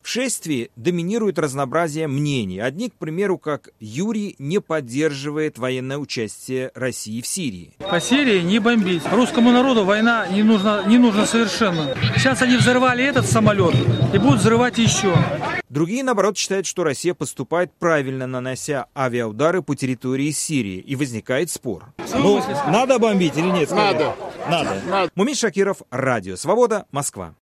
беседует с участниками "Русского марша" о Сирии